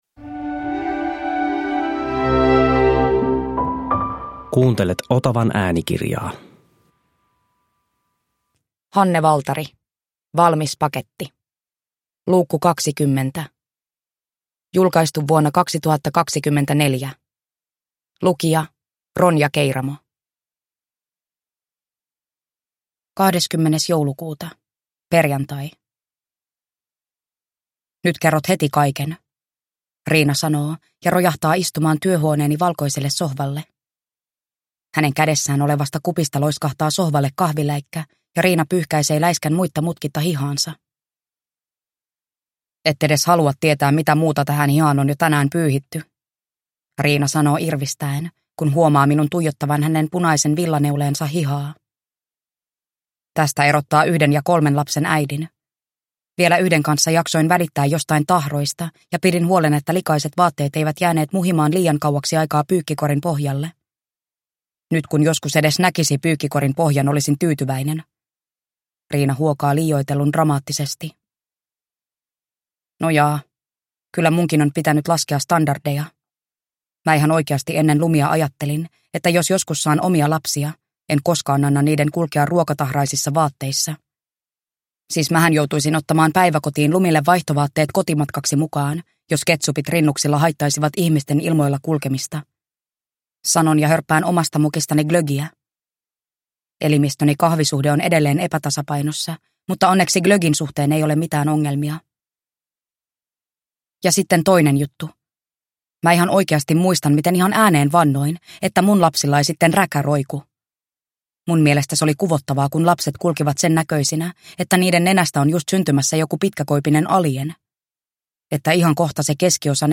Valmis paketti 20 (ljudbok) av Hanne Valtari